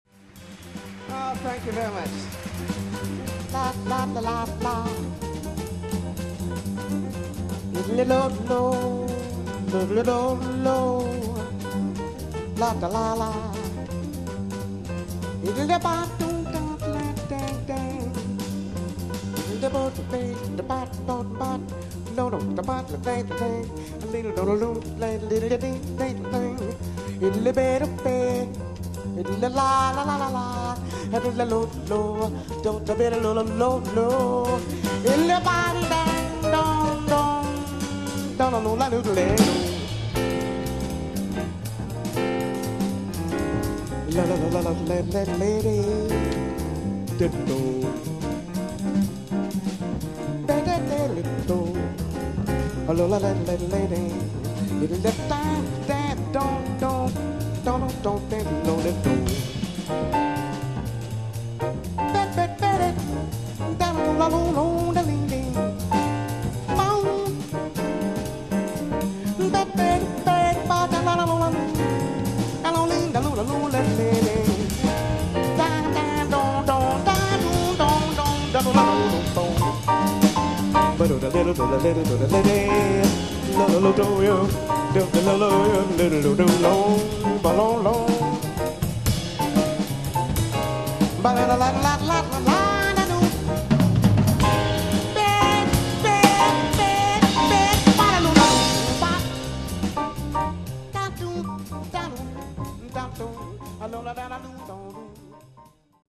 un live